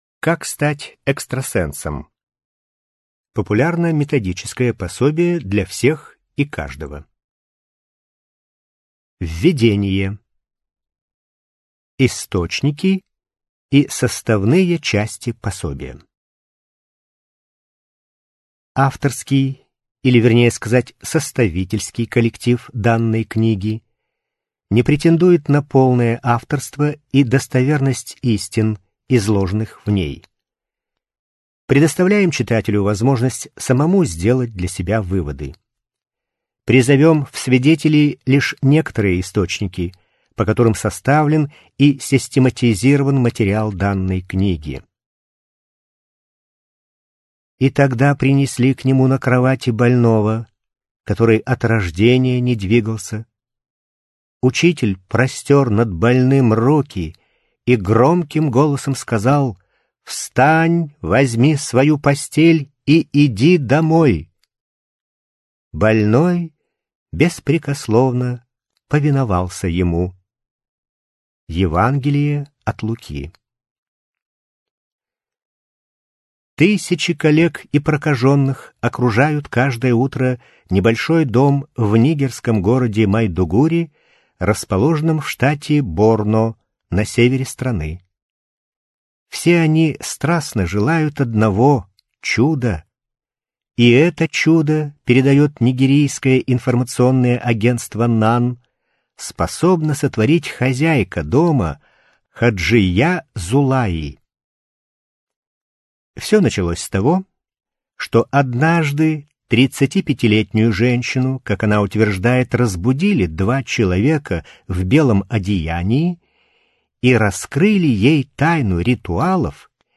Аудиокнига Как стать экстрасенсом | Библиотека аудиокниг